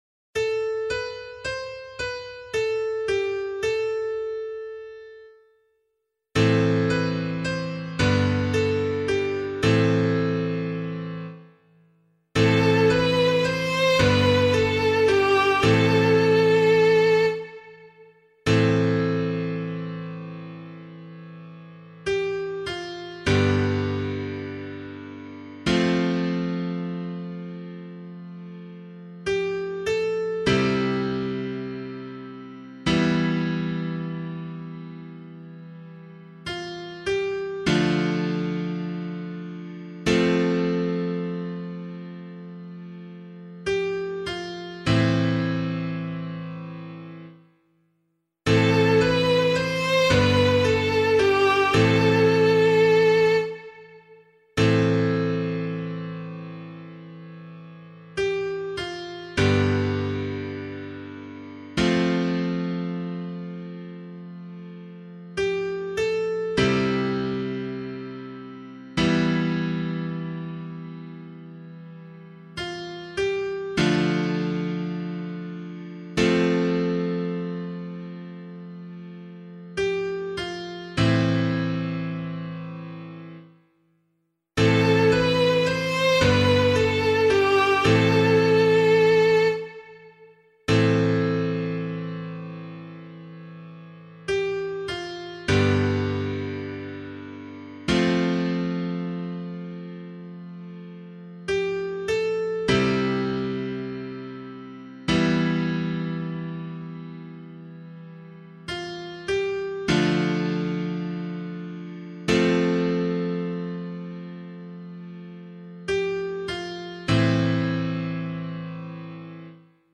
037 Ordinary Time 3 Psalm B [LiturgyShare 4 - Oz] - piano.mp3